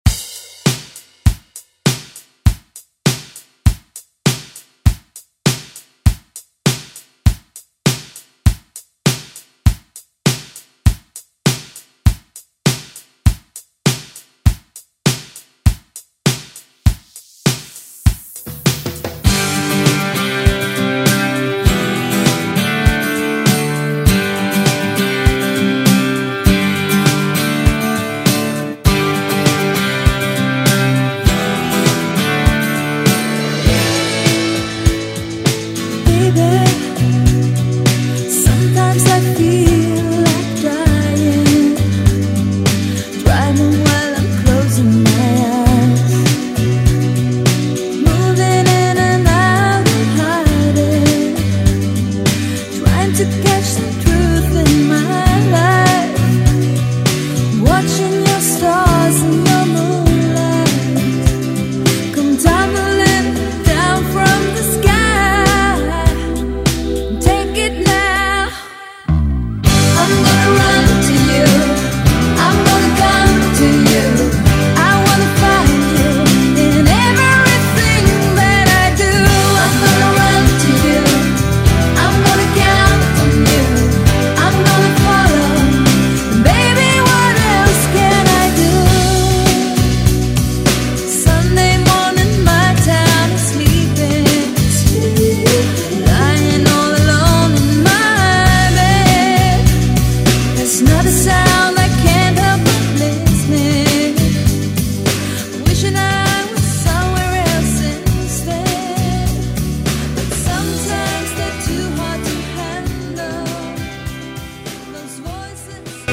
Electronic Dance Pop Music
125 bpm
Genres: 2000's , DANCE , RE-DRUM